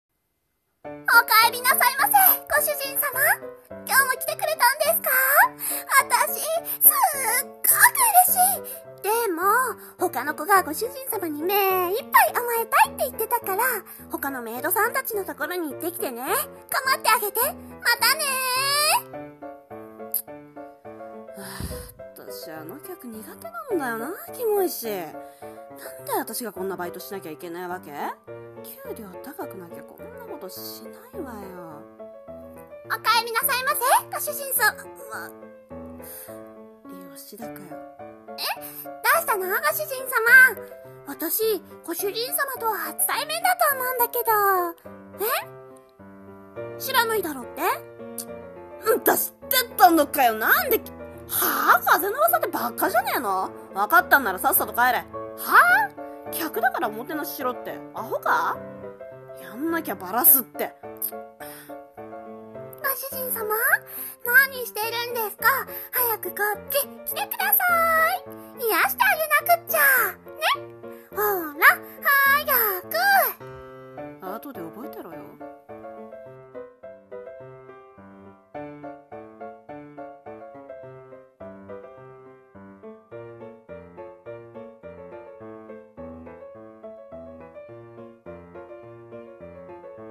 【一人声劇台本】メイド喫茶のメイド男子(女子)の憂鬱【演技力・演じ分け】